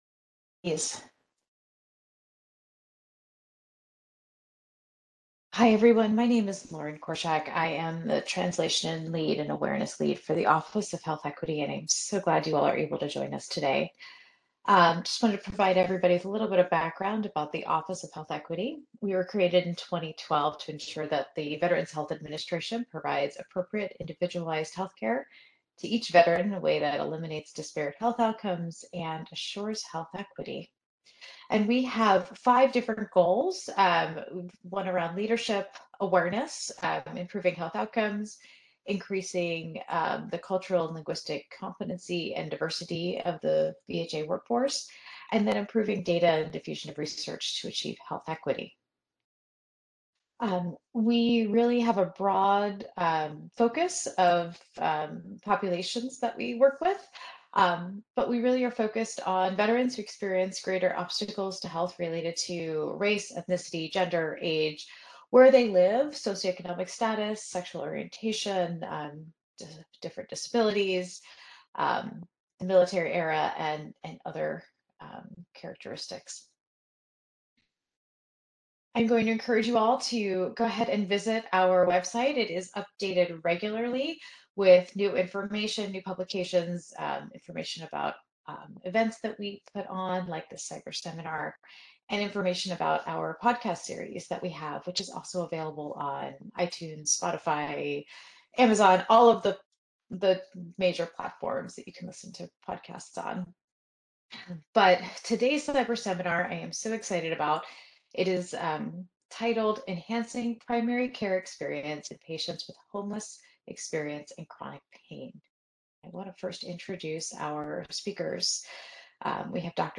PhD Seminar date